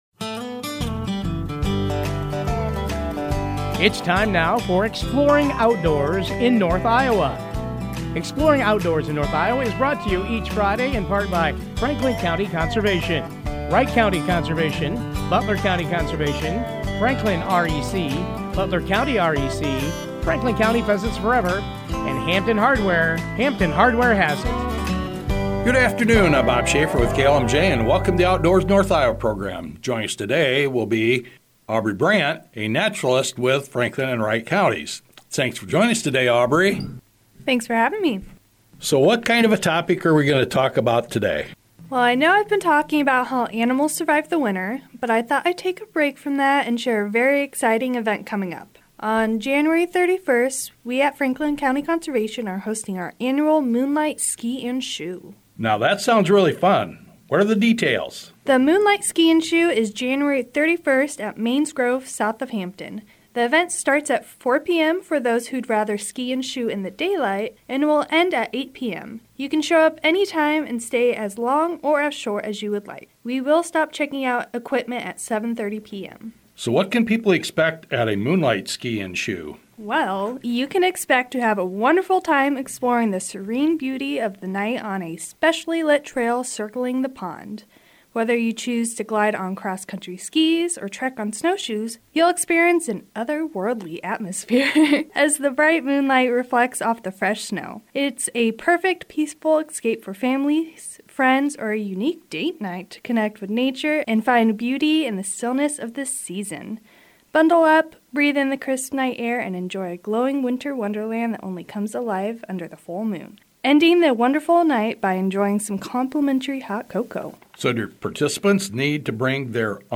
Full interview below